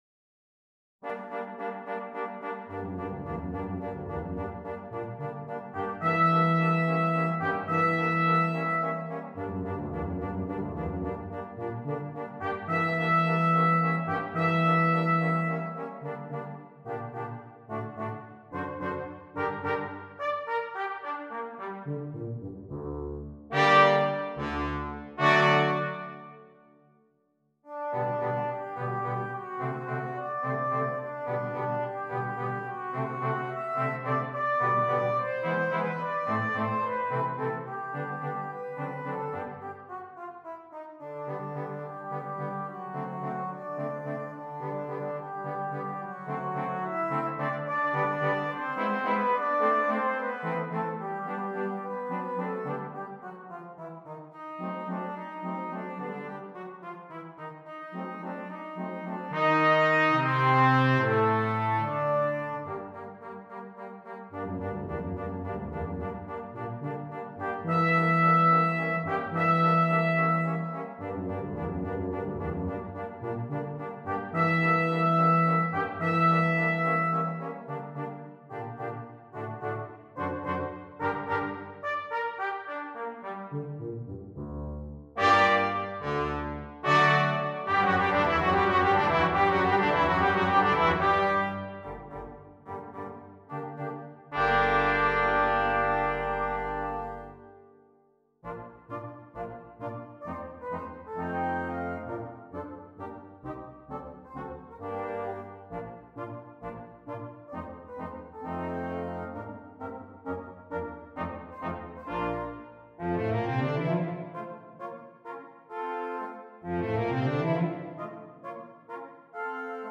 • Brass Quintet